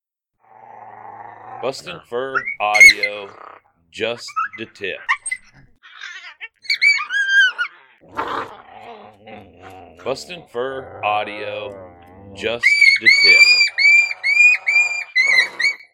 MotoMoto and Tazzy were recently paired up together as a breeding pair and as usual, the fighting and bickering ensued. Packed with growls, squalls and yips, making it an excellent sound to run during the territorial season.
• Product Code: pups and fights